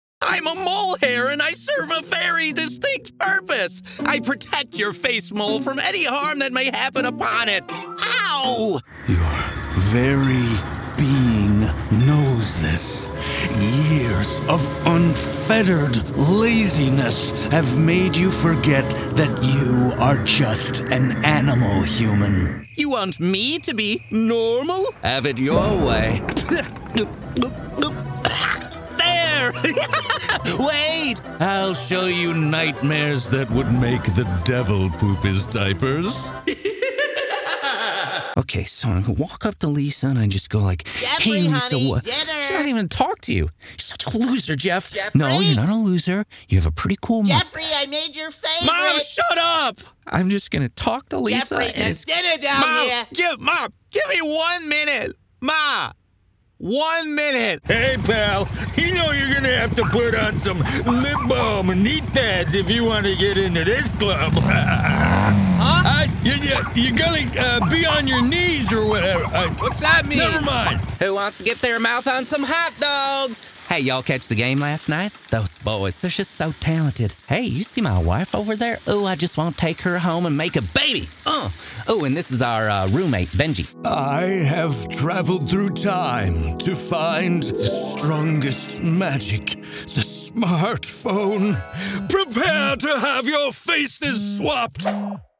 Male Talent
Character Demo